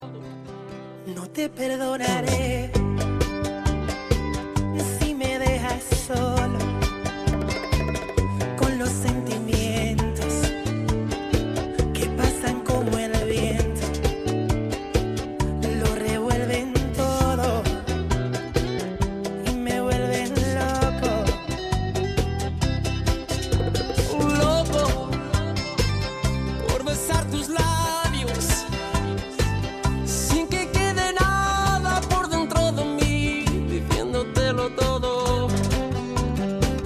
• Качество: 128, Stereo
гитара
спокойные